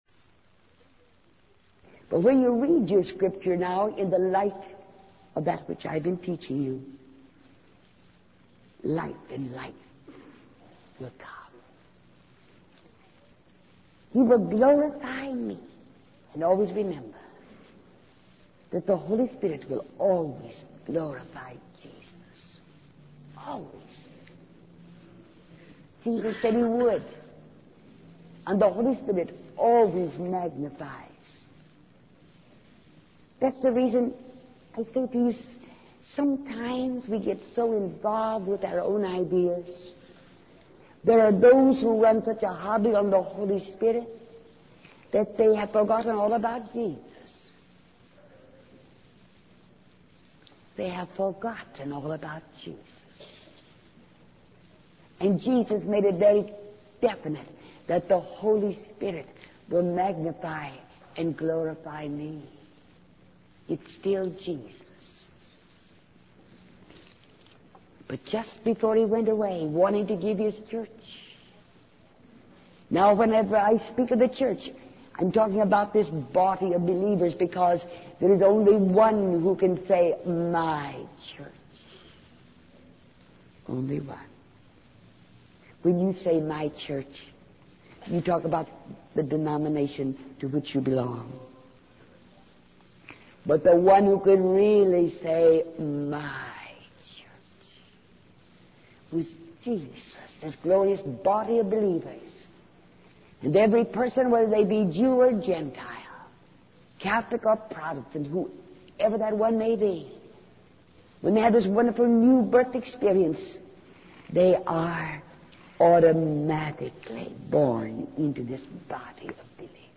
In this sermon, the preacher discusses the limitations of our physical bodies in experiencing the power of God.